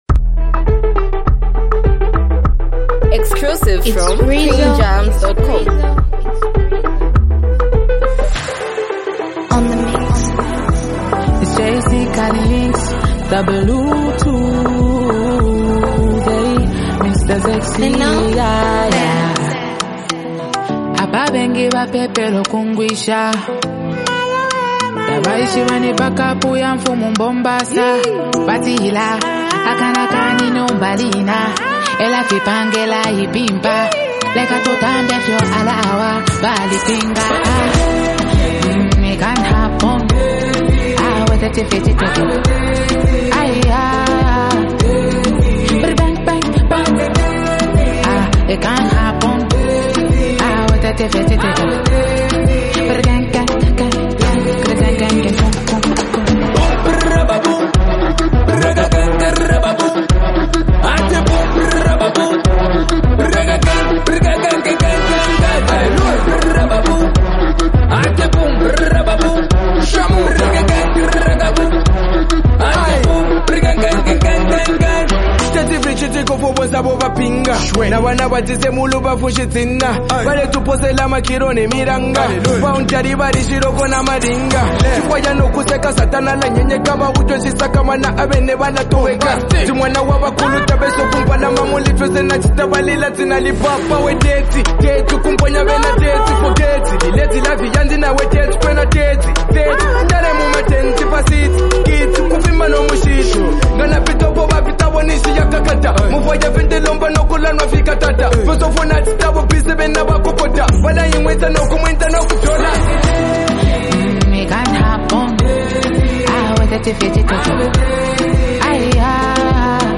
heartfelt vocals
love song